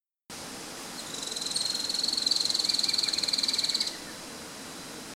Tiluchi Enano (Terenura maculata)
Misiones-junio-2018-1164-tiluchi-enano.mp3
Nombre en inglés: Streak-capped Antwren
Localidad o área protegida: Reserva Privada y Ecolodge Surucuá
Condición: Silvestre
Certeza: Fotografiada, Vocalización Grabada